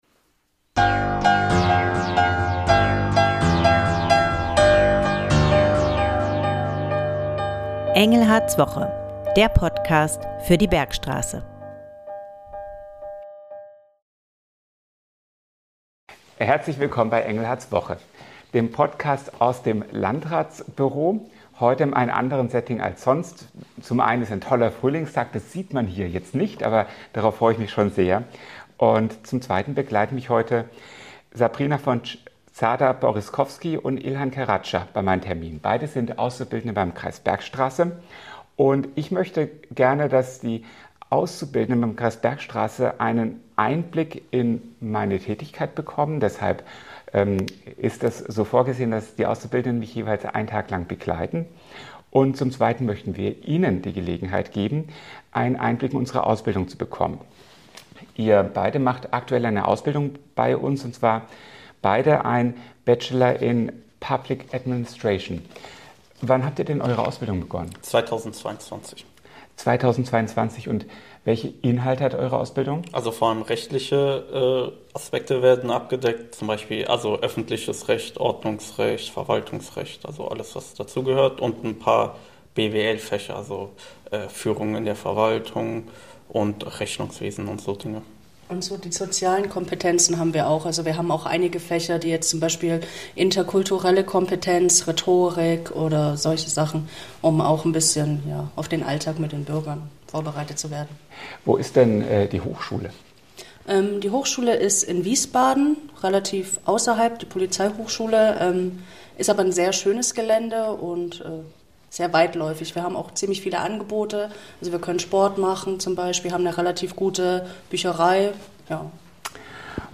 Schwerpunkt: Interview mit dualen Studenten des Kreises